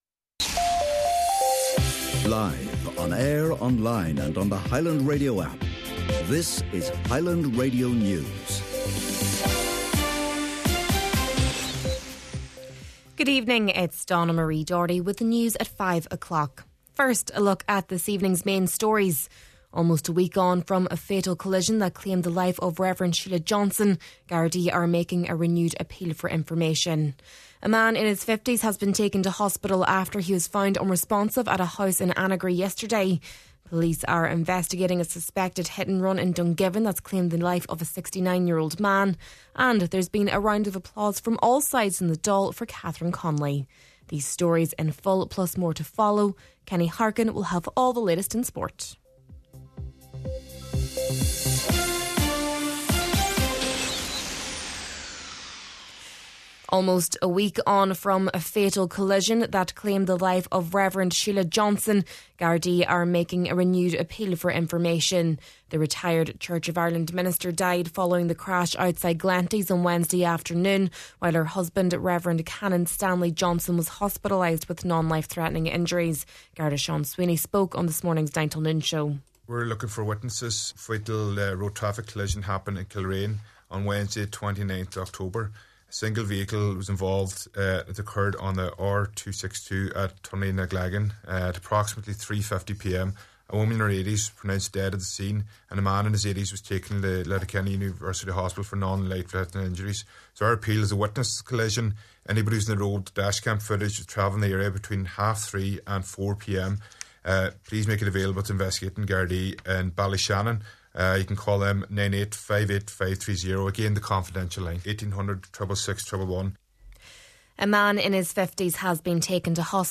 Main Evening News, Sport and Obituaries – Tuesday, November 4th